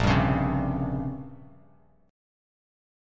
A#m6add9 chord